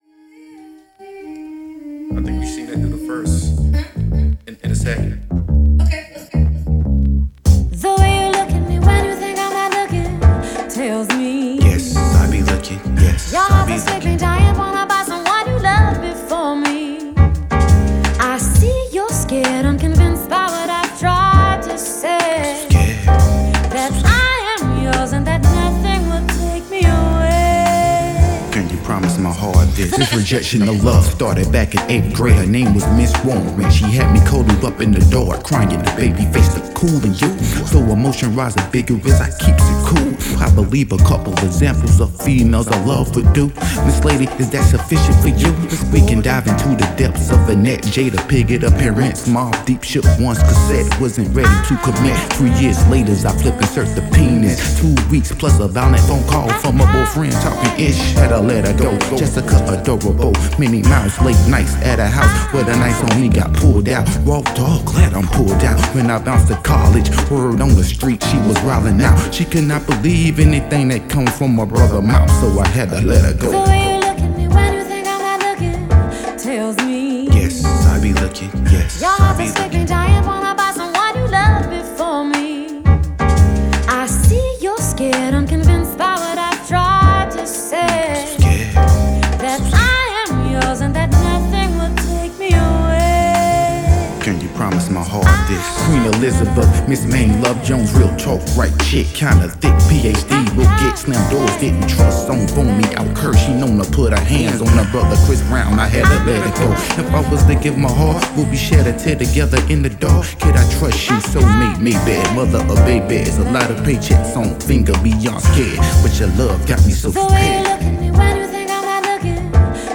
dope remix
Perfect mix of Soul & Jazz…
jazzy track